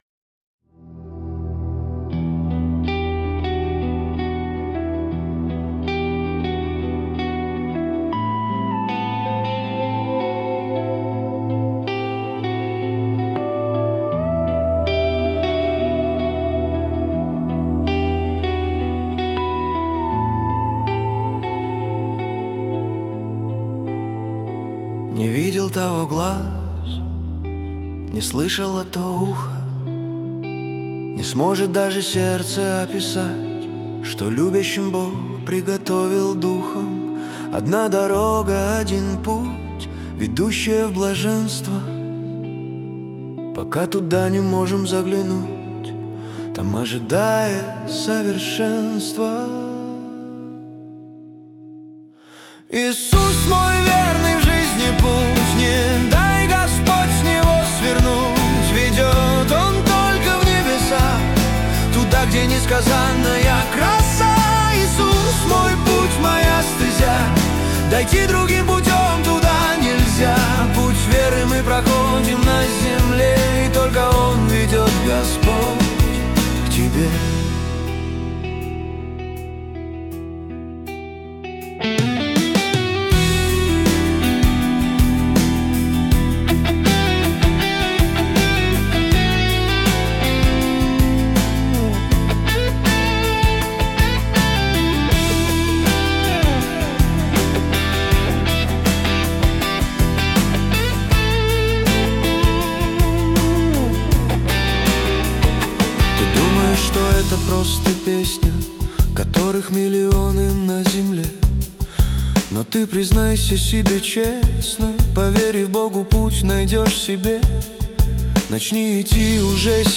песня ai
224 просмотра 747 прослушиваний 107 скачиваний BPM: 81